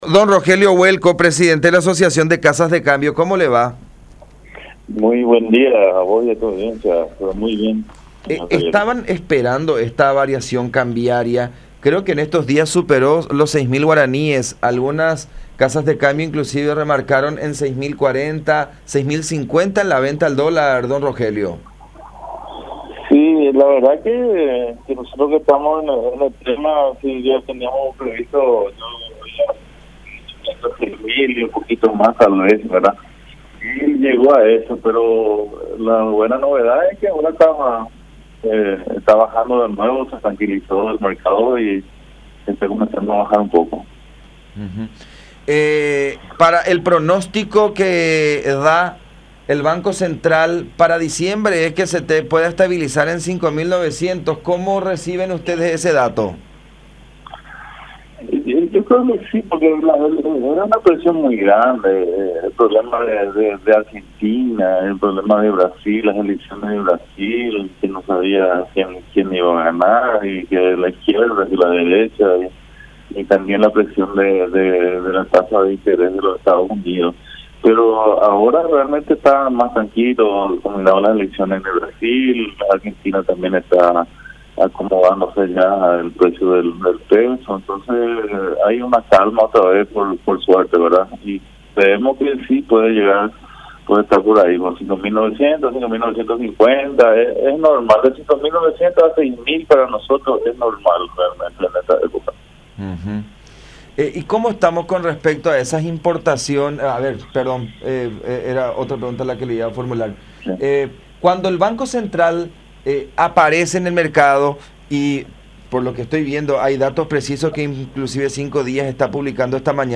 “Ya hay una calma”, dijo en comunicación con La Unión, afirmando que tiene las mismas estimaciones del Banco Central del Paraguay (BCP), de que el dólar para las primeras semanas de diciembre descenderá de nuevo a G. 5.900 aproximadamente.